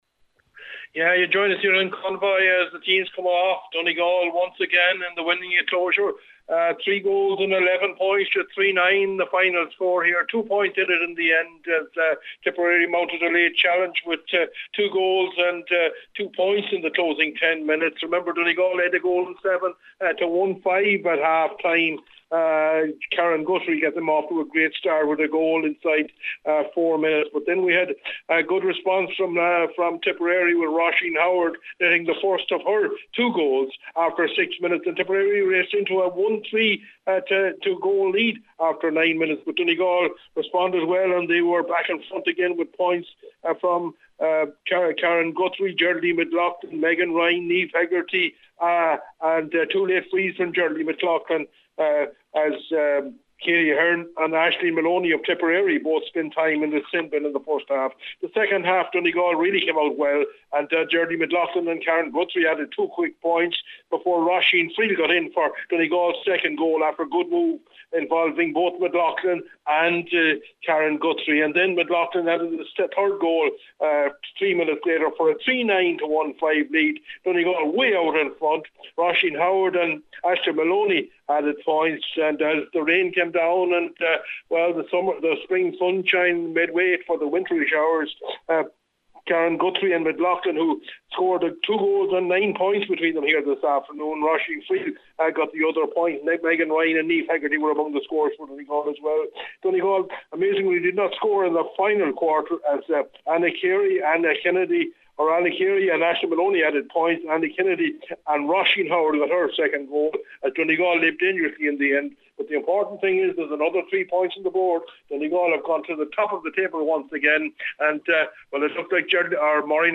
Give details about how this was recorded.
full-time report from Convoy